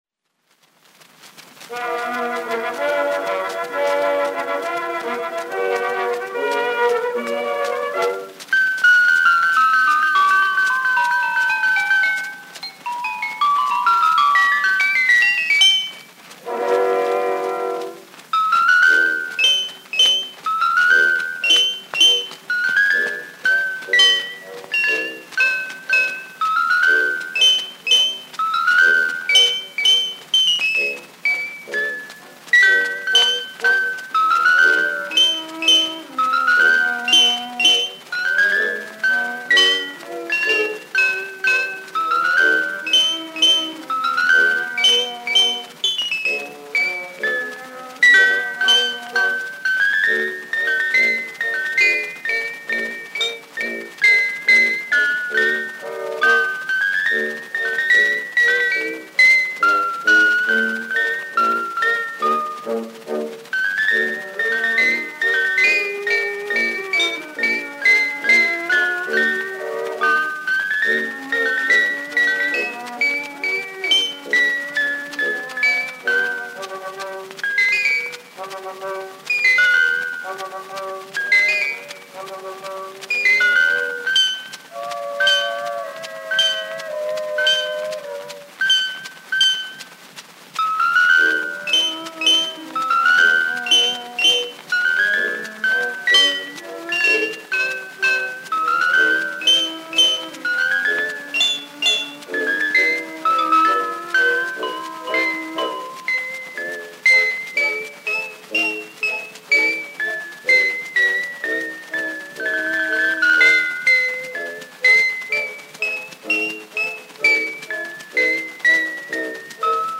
Bells, orchestra accompaniment.
Glockenspiel with orchestra.